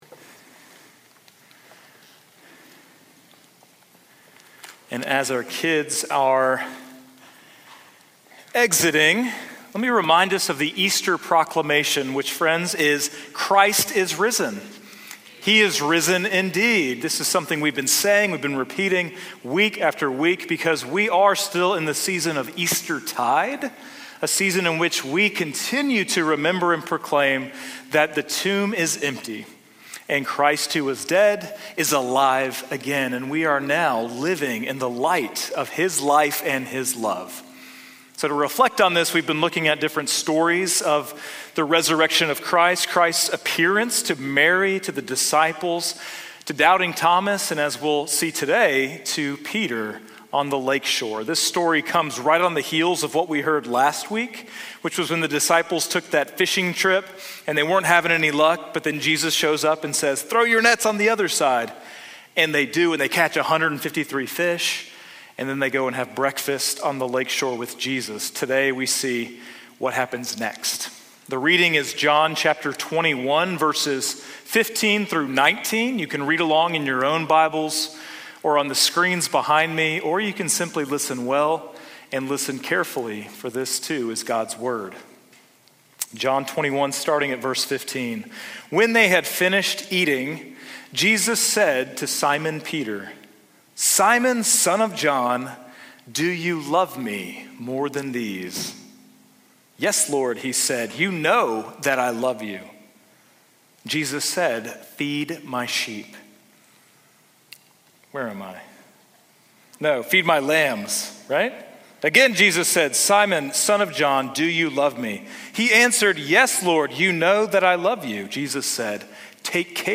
A sermon series on the "I AM" statements found in the Gospel of John.
Sermon-Audio-5.18.25.mp3